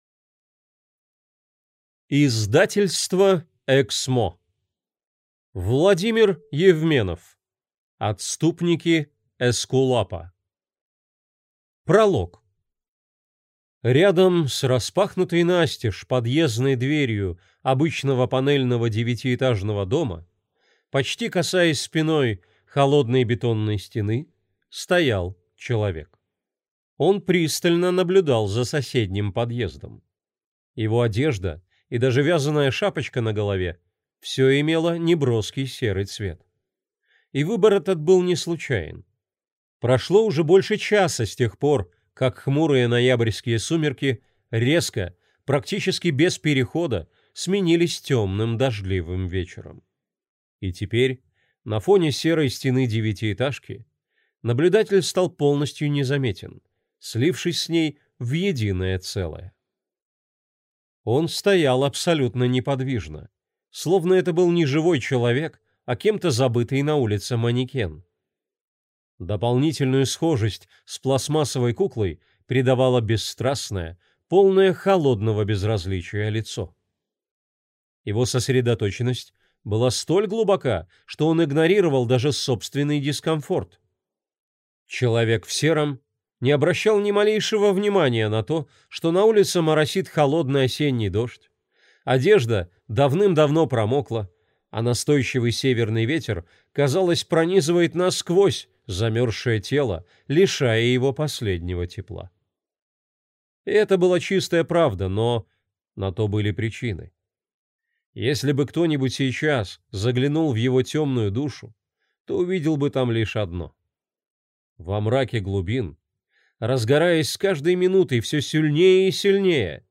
Аудиокнига Отступники Эскулапа | Библиотека аудиокниг